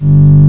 1 channel
perfectshieldsound.mp3